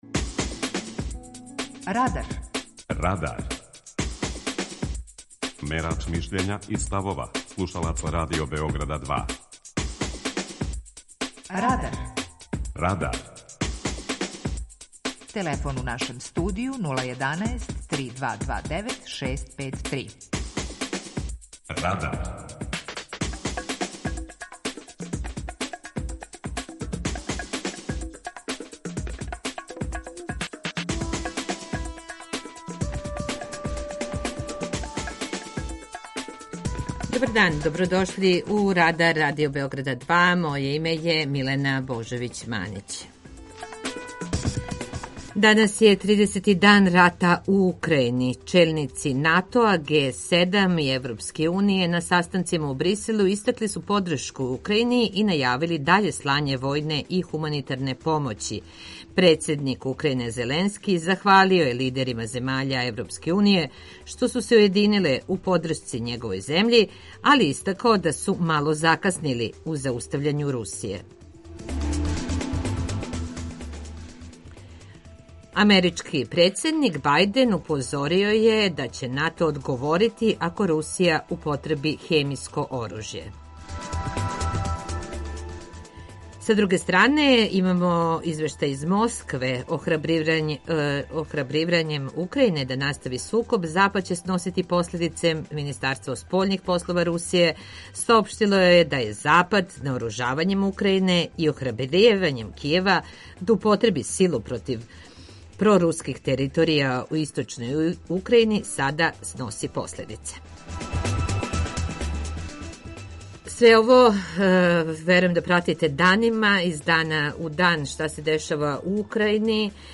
Питање Радара је Да ли ће бити Трећег светског рата? преузми : 19.26 MB Радар Autor: Група аутора У емисији „Радар", гости и слушаоци разговарају о актуелним темама из друштвеног и културног живота.